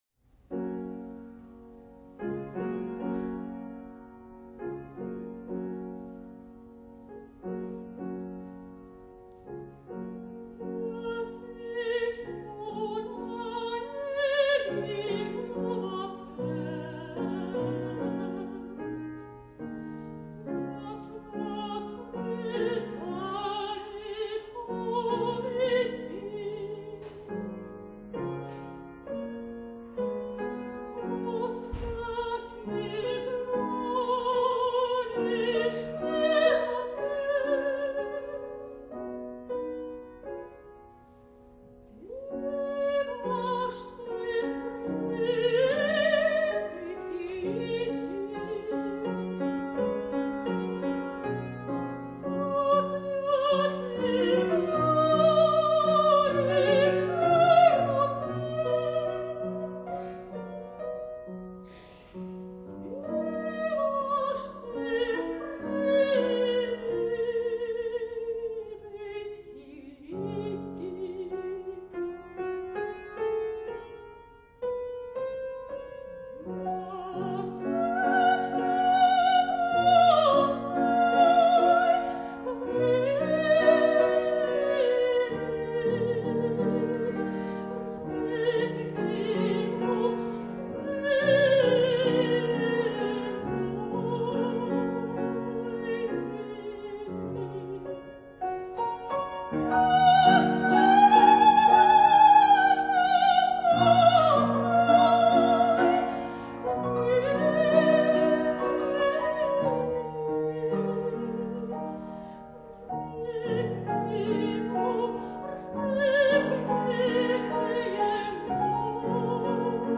Radiant Lithuanian soprano